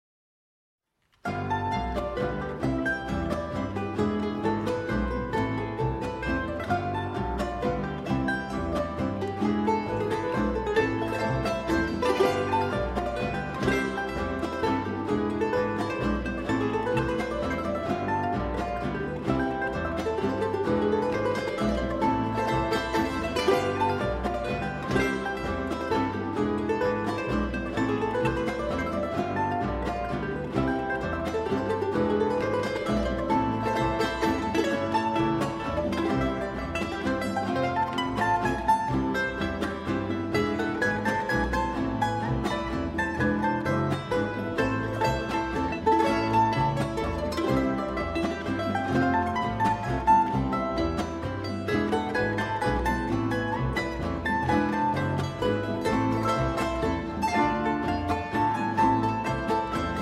Chamber Ensemble
Traditional Argentina